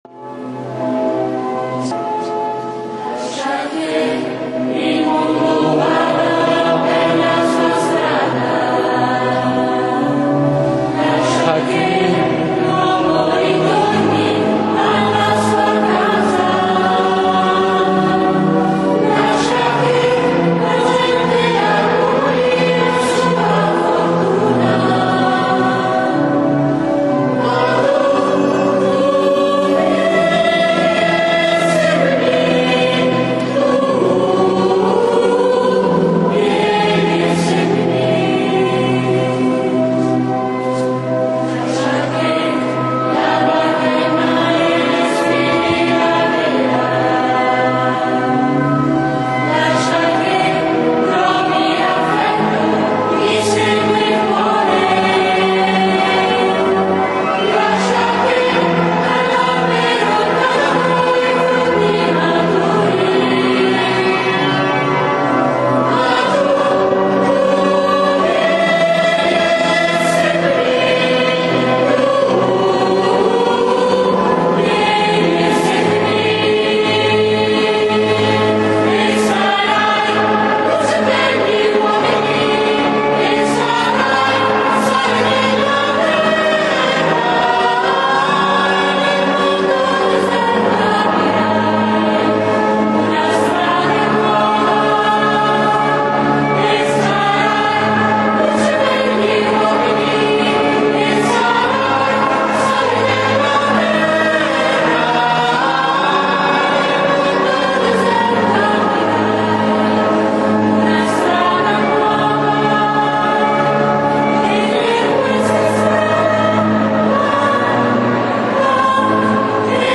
canto: Vieni e seguimi